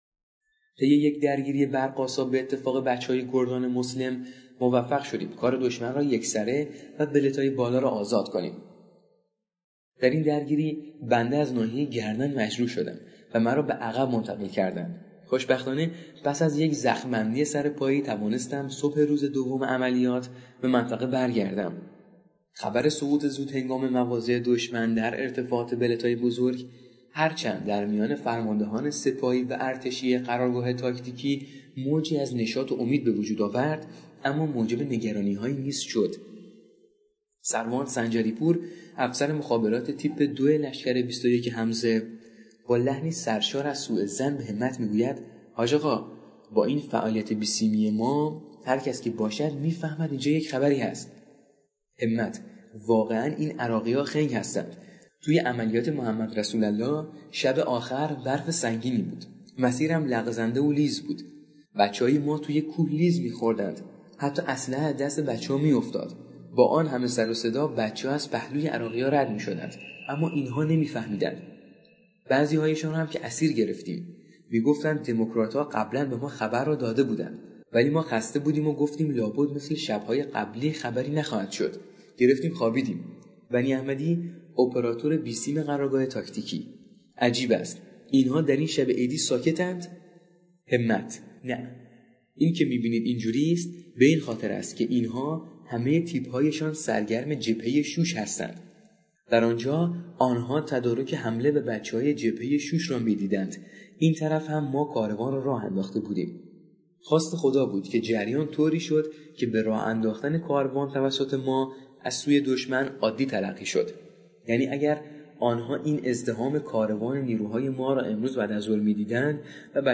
صوتی/ کتاب همپای صاعقه - نبرد فتح